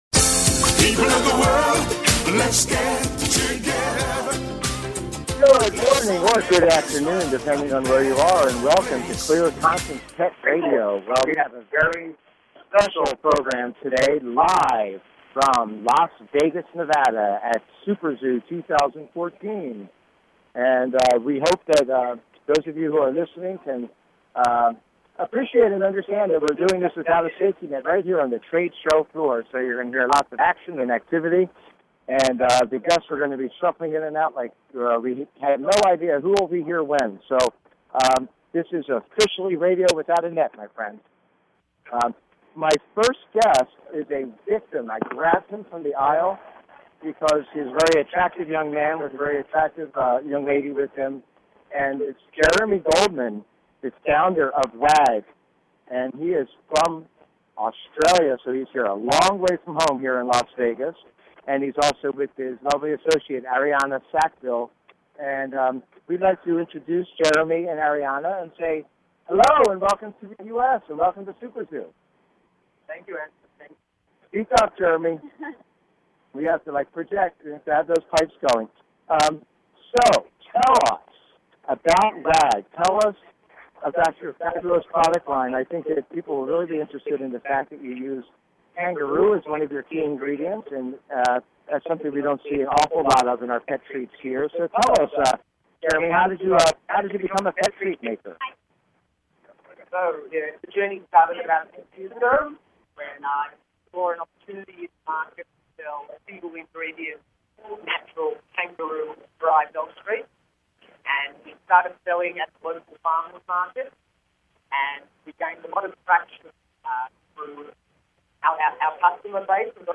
Talk Show Episode
SPECIAL LIVE "On the Road" EVENT.
The audio may not be the best, but the information is not to be missed!